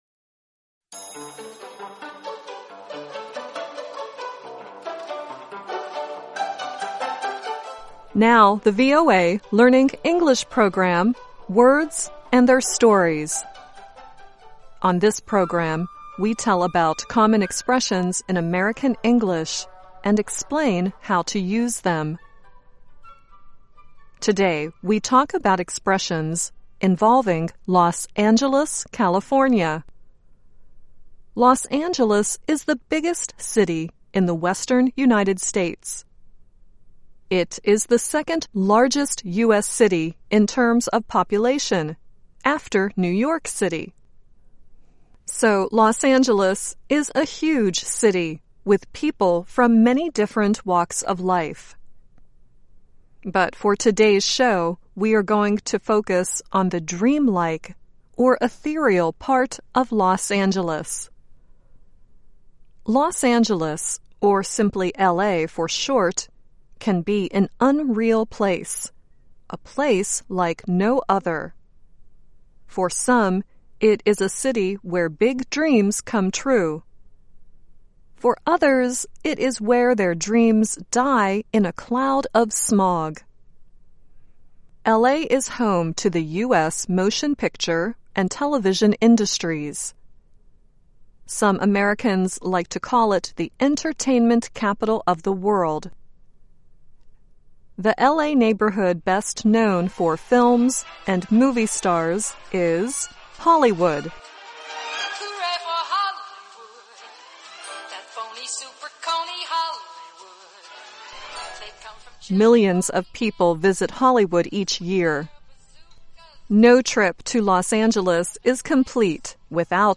The two songs used in this program are "Hooray for Hollywood" sung by Doris Day and "I Love L.A." sung and written by Randy Newman.